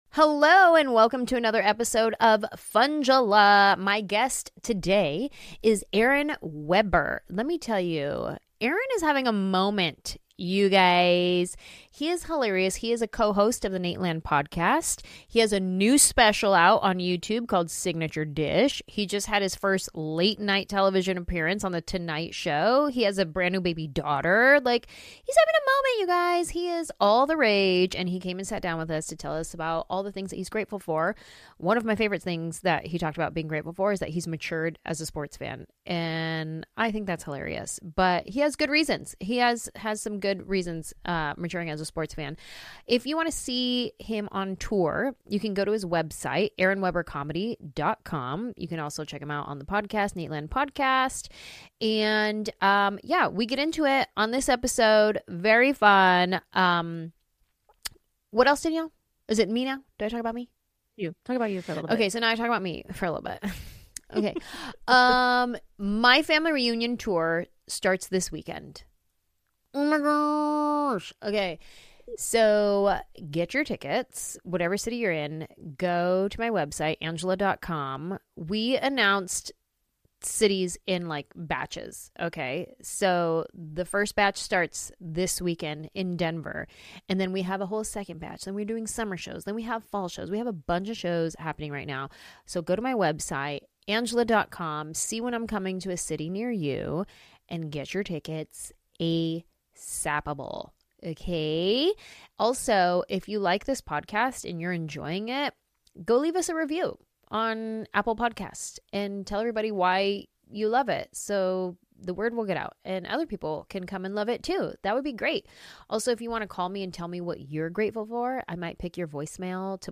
So Anjelah was grateful he could stop by the Funjelah studio to tell us about how much he's ticked off about no one supporting him in his maditude over Nashville traffic.